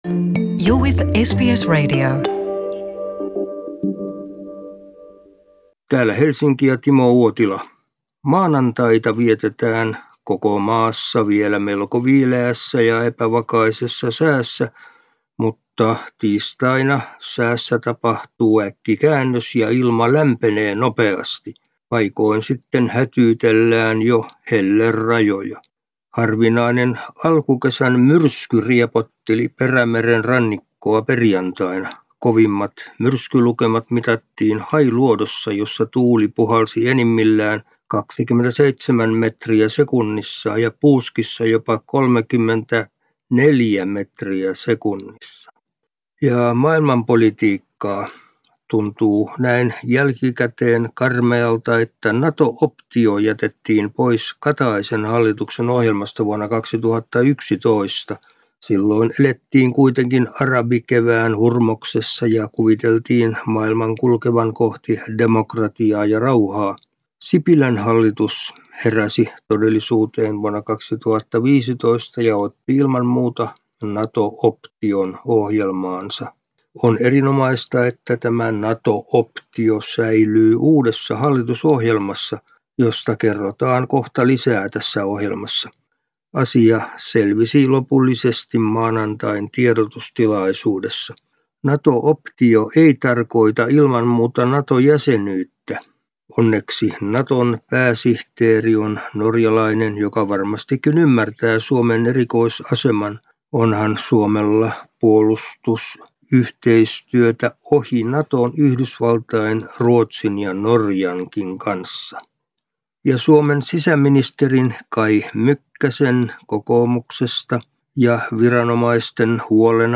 ajankohtaisraportti 5.6.19